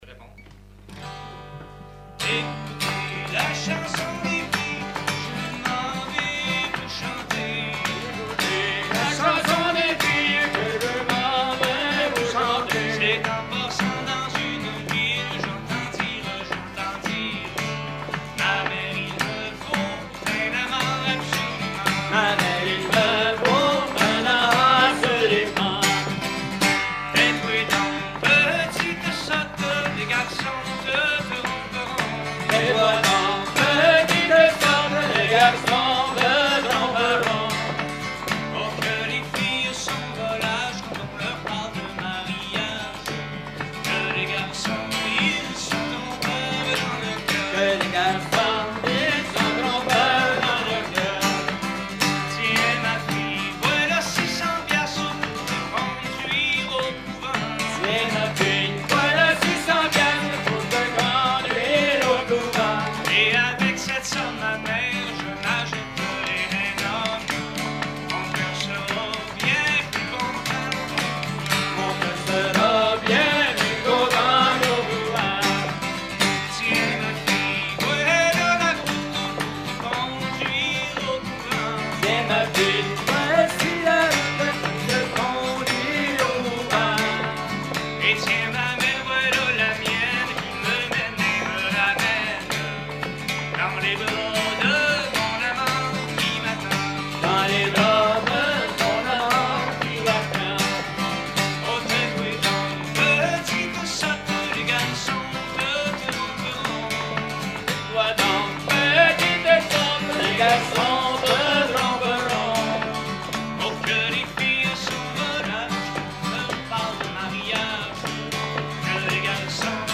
Genre laisse
Concert à la ferme du Vasais
Pièce musicale inédite